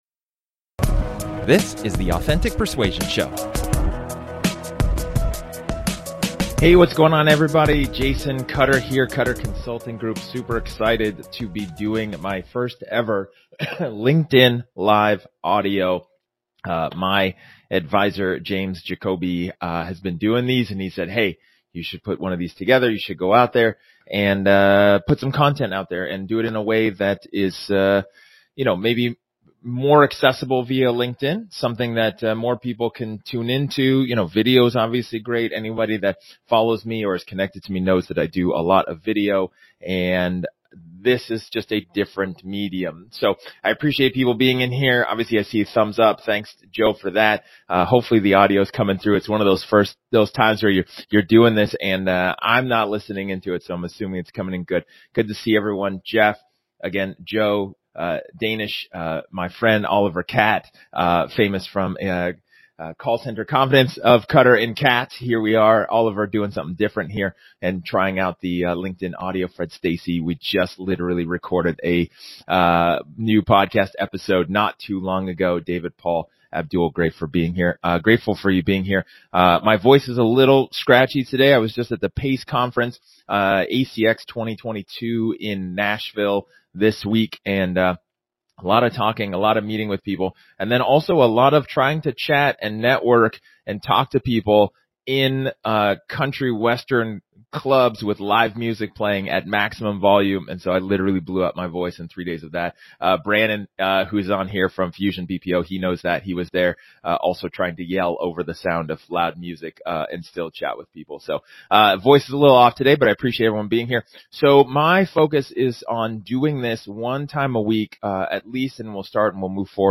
[538] LinkedIn Live Audio Replay: Understanding Your Prospects